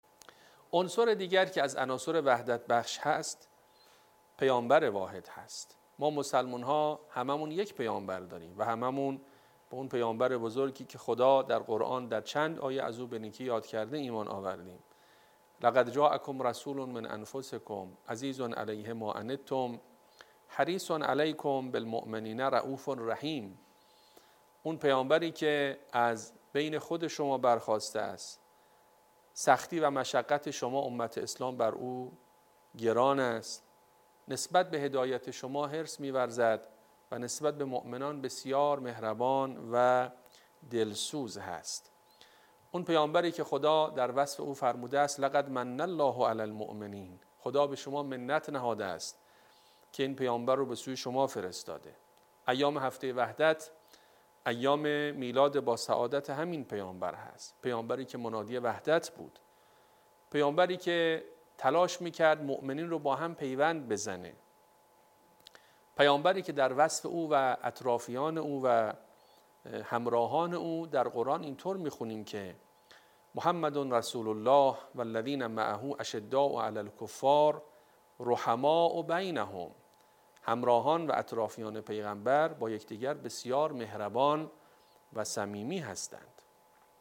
حاج‌ابوالقاسم در گفت‌وگو با ایکنا: